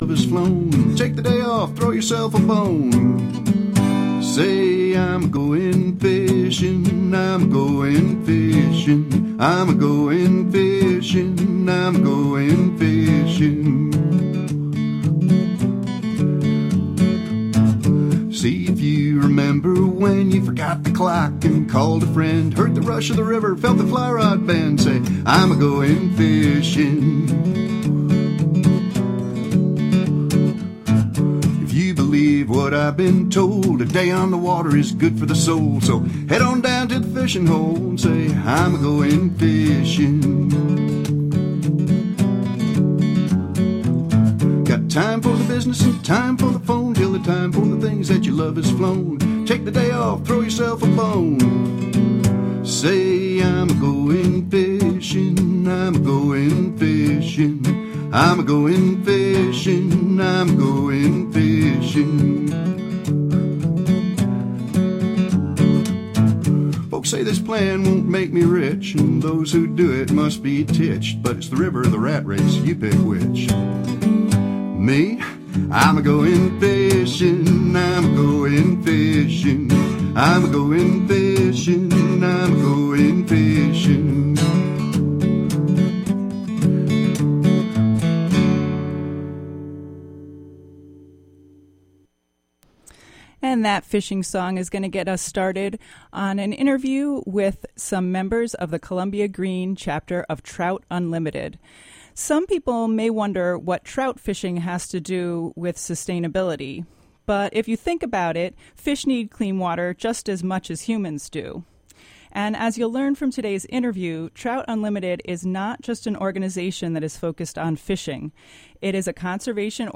It is a national conservation organization with local chapters filled with folks who care about protecting clean water. Tune in for an interesting interview with members of the Columbia-Greene Chapter of Trout Unlimited .
Plus, your favorite fishing songs.